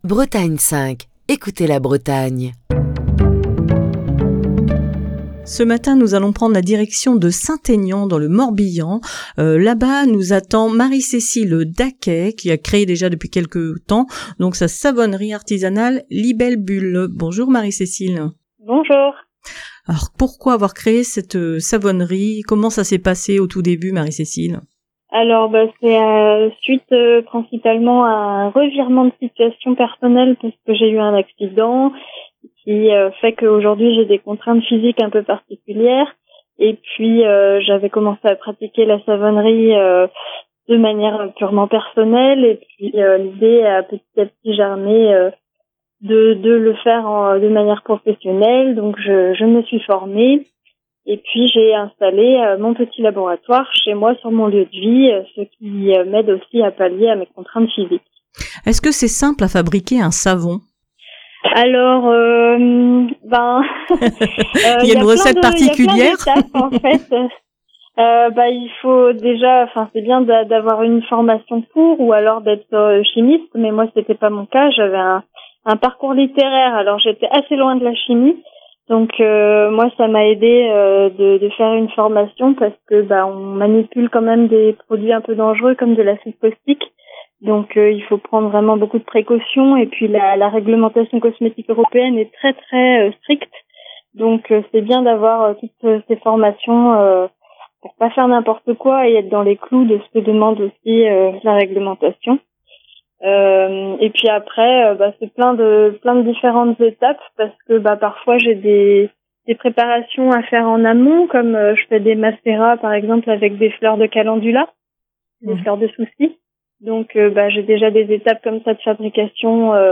Aujourd'hui dans le coup de fil du matin